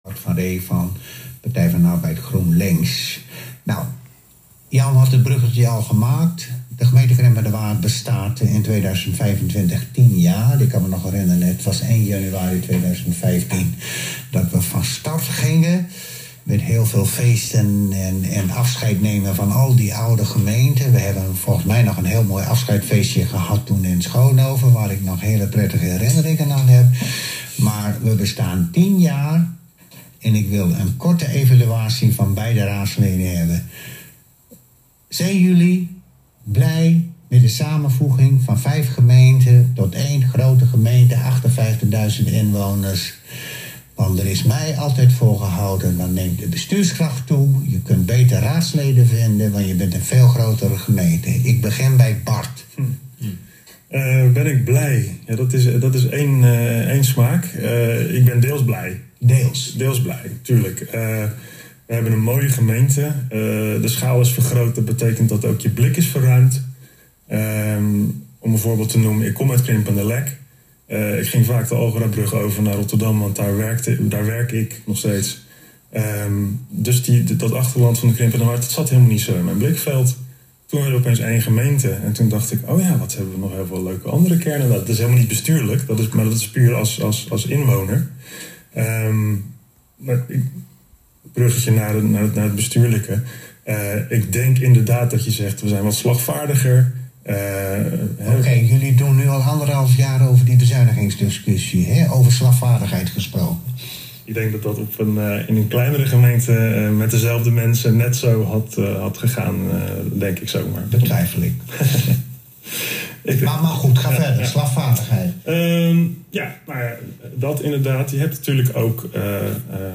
Dat deel van de radio-uitzending wordt bijgevoegd. Het ging, tot onze verrassing, al snel over het optreden van Pro Krimpenerwaard in die periode.